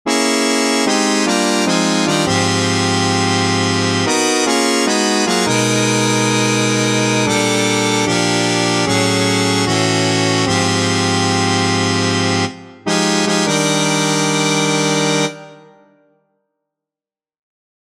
Key written in: E♭ Major
How many parts: 4
Type: Other male
All Parts mix: